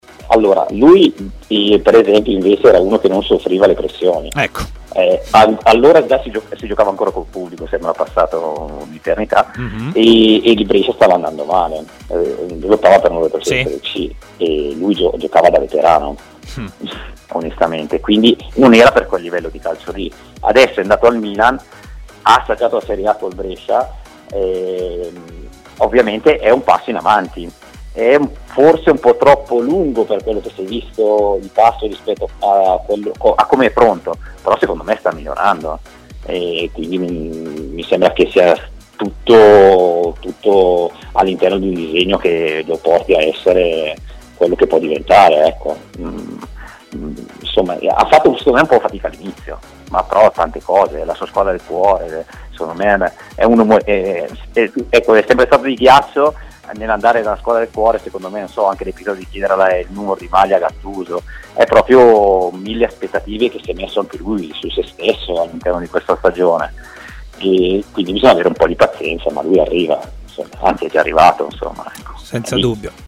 in diretta a Stadio Aperto, trasmissione di TMW Radio